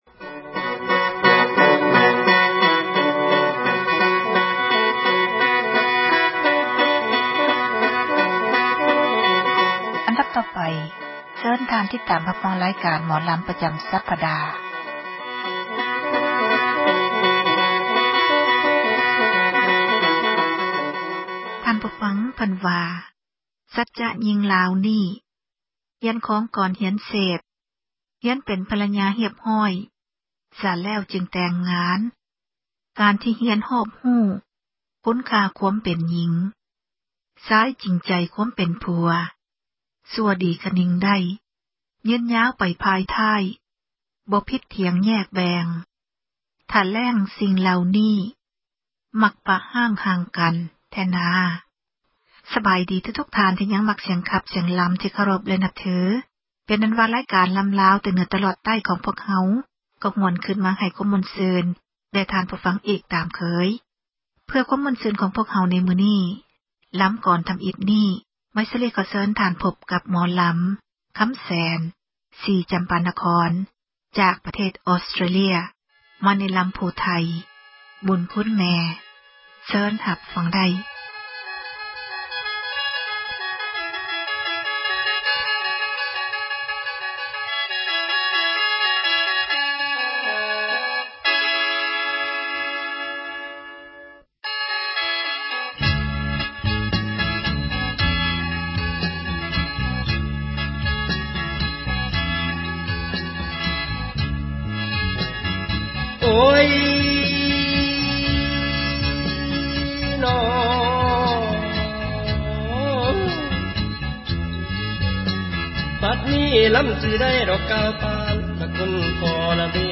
ຣາຍການ ໝໍລຳລາວ ປະຈຳ ສັປດາ ສເນີໂດຍ